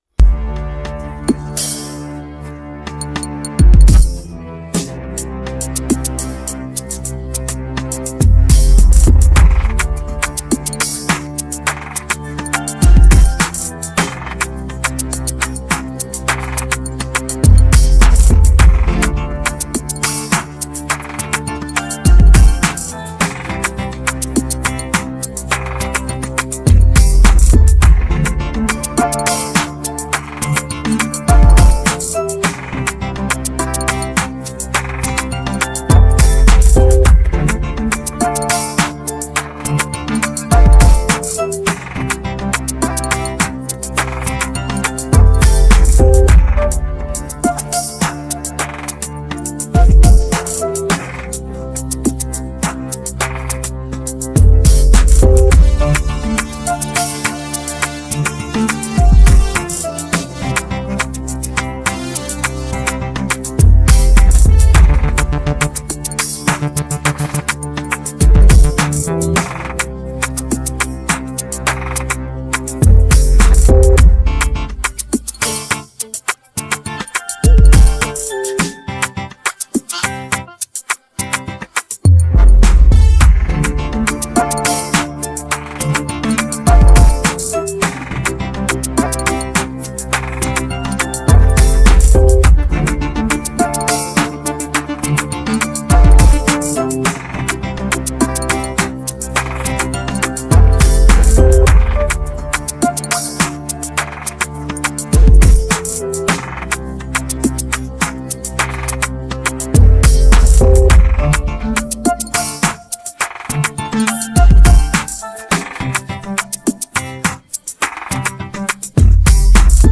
Afro dancehallAfrobeats